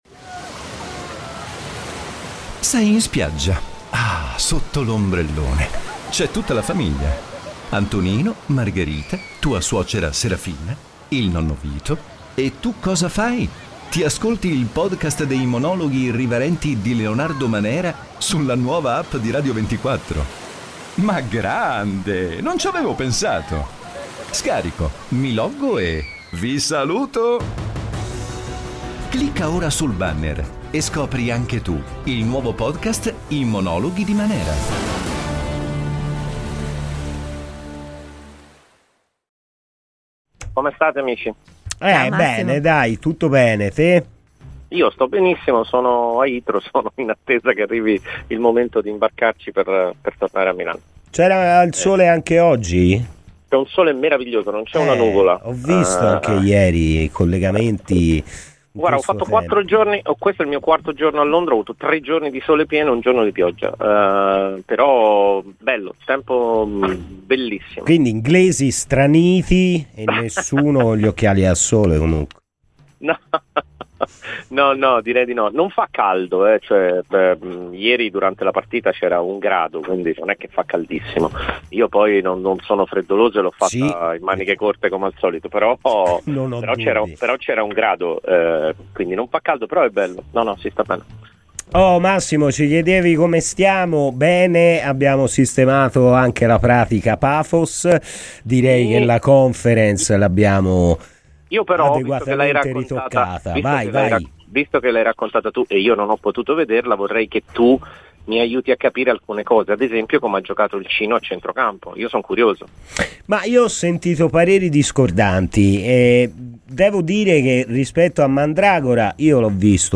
Il giornalista e commentatore televisivo Massimo Marianella è intervenuto oggi a Radio Firenzeviola, durante 'Palla al centro'.